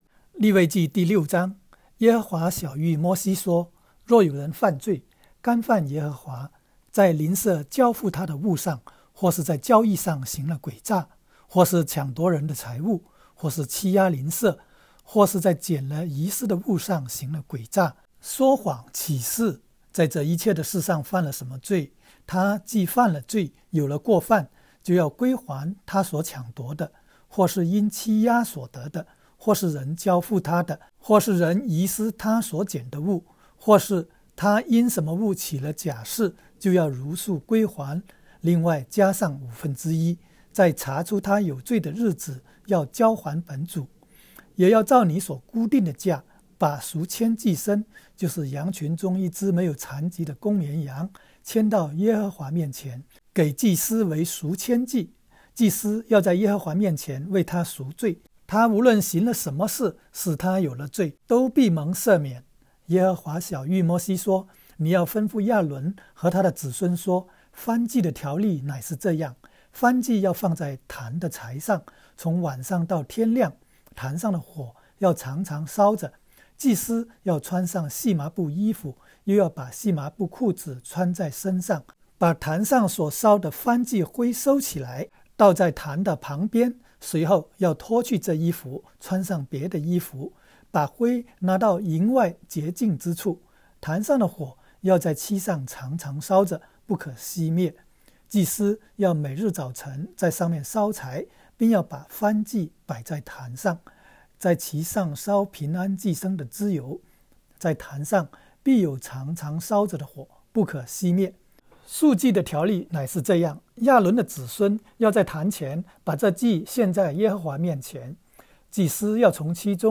利06（经文-国）.m4a